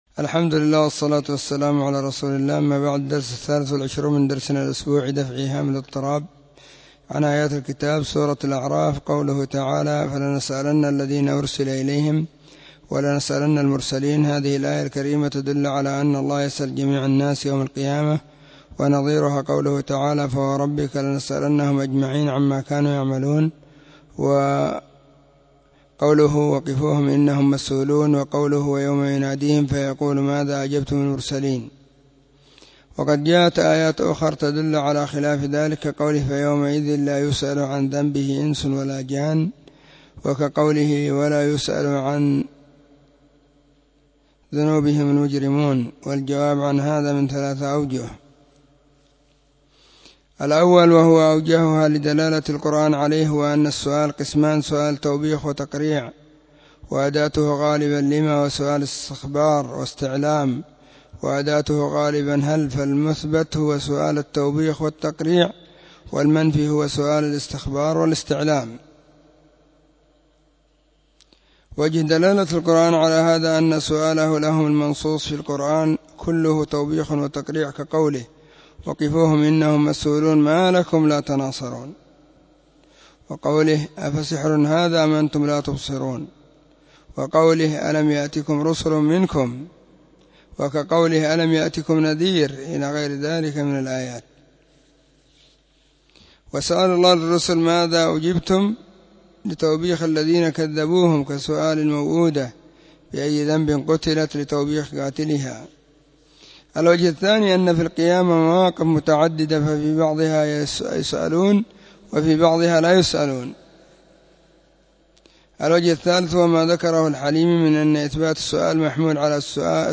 ⏱ [بعد صلاة الظهر في كل يوم الخميس]
📢 مسجد الصحابة – بالغيضة – المهرة، اليمن حرسها الله.